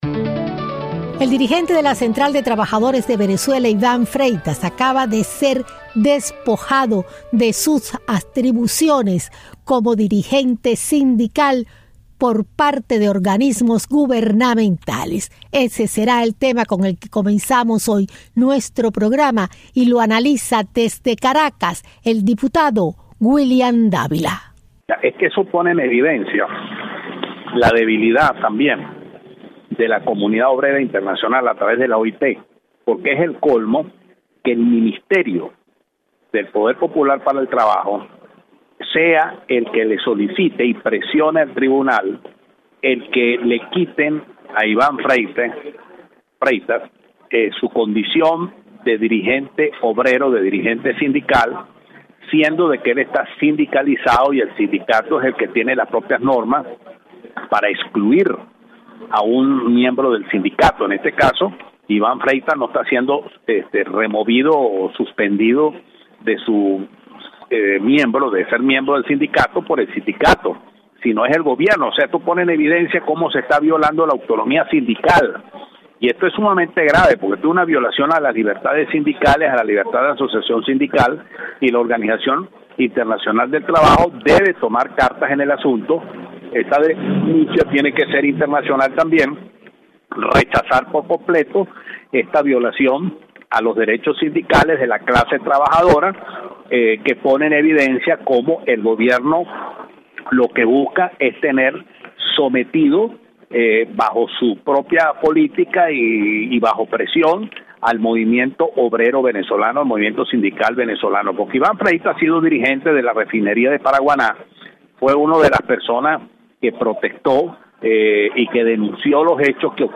Expulsado dirigente sindical venezolano: nos comenta Diputado venezolano William Davila. Ademas, presidente ecuatoriano Rafael Correa pretende modificar Constitucion del pais.